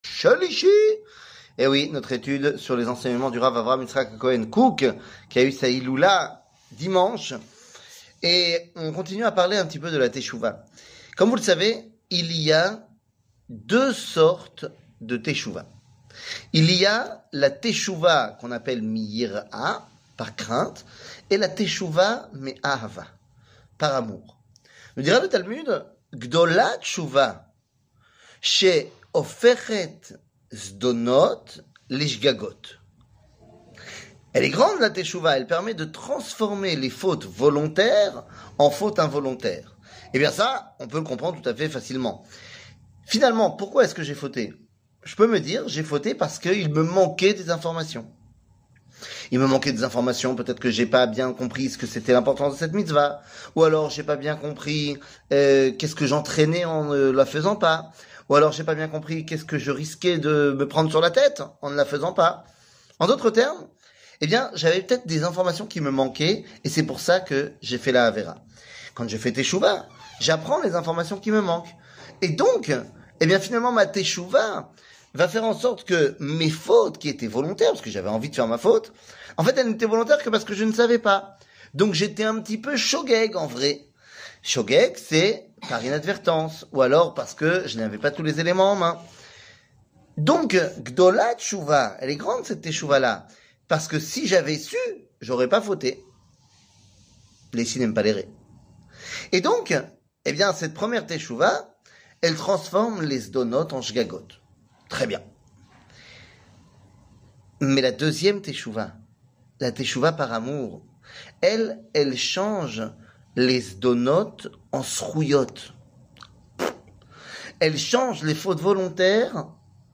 שיעור מ 22 אוגוסט 2023
שיעורים קצרים